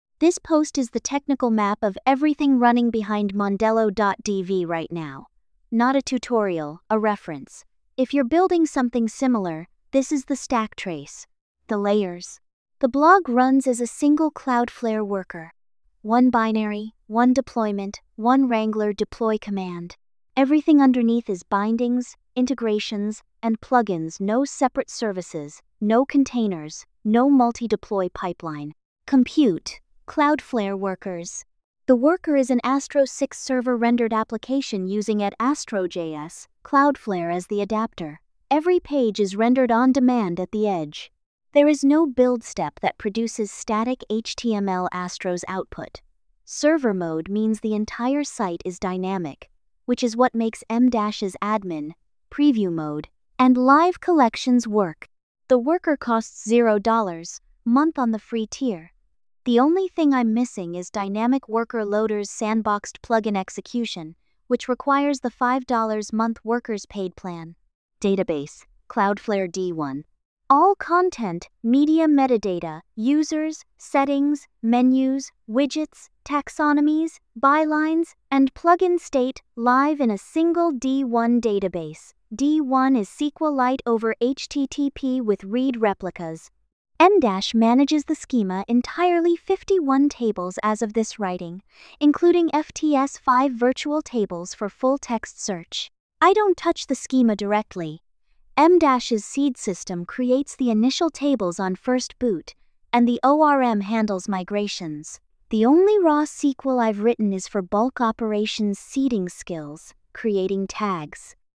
AI-narrated with MiniMax speech-2.8-hd · 1:52. Hit play or use the controls.